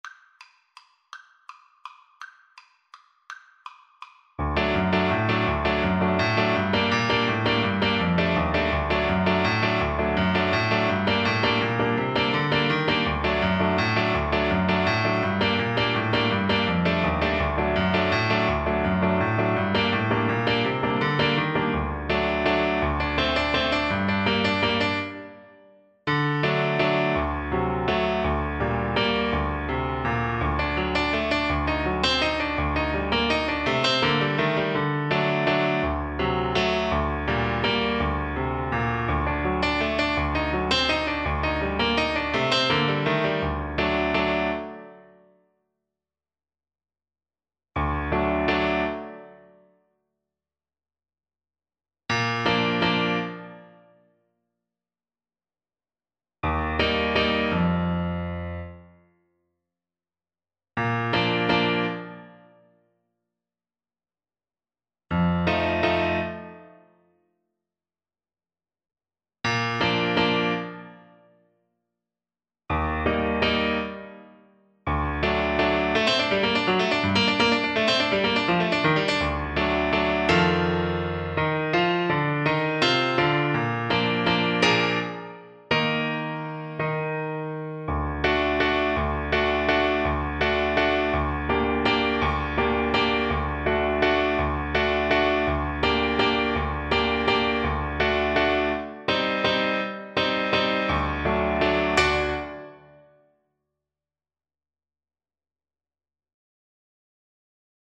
3/4 (View more 3/4 Music)
Classical (View more Classical Trumpet Music)